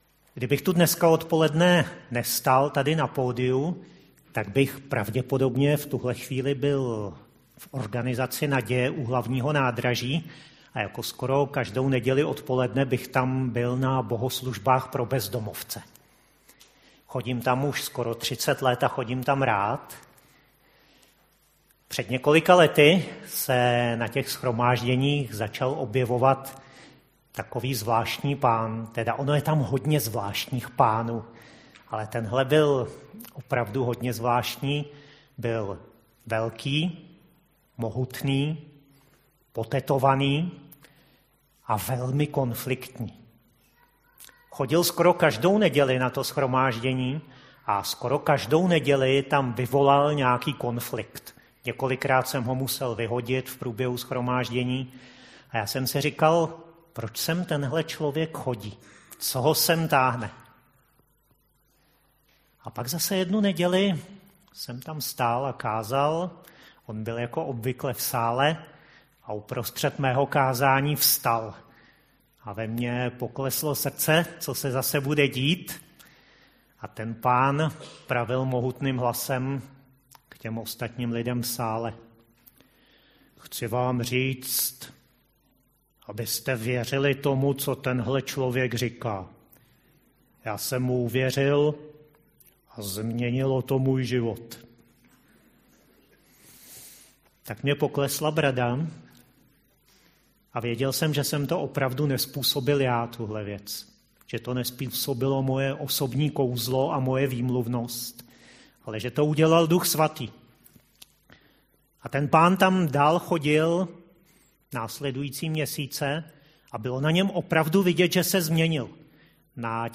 Záznam kázání ze společného shromáždění Církve Křesťanská společenství.